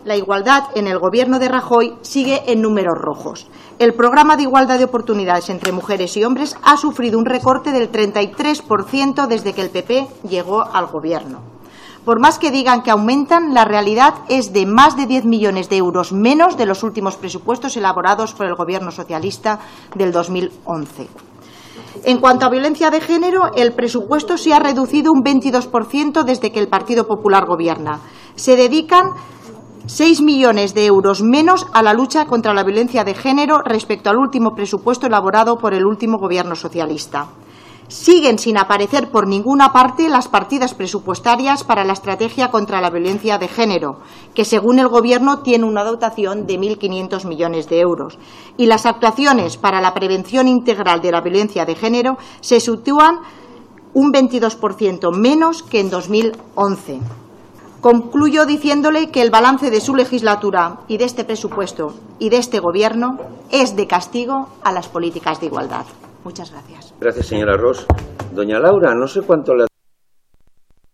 Fragmento de la intervención de Susana Ros en la Comisión de Sanidad y Servicios Sociales.